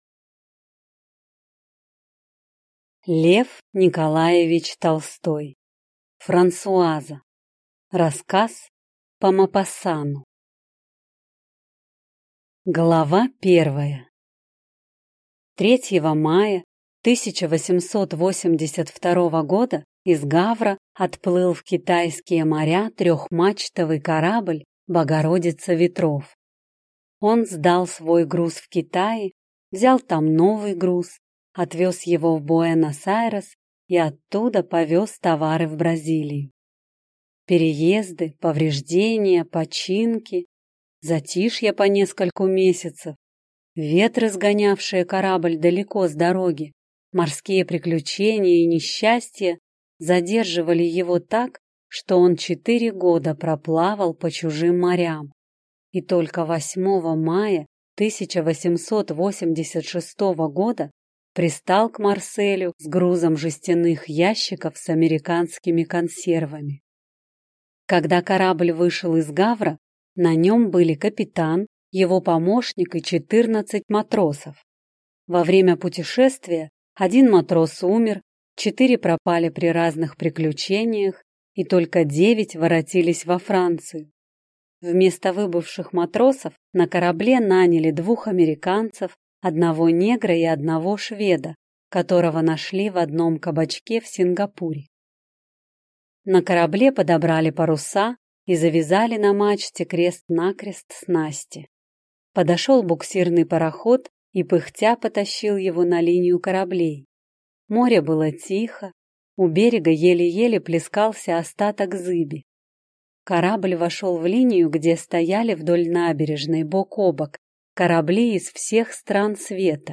Аудиокнига Франсуаза | Библиотека аудиокниг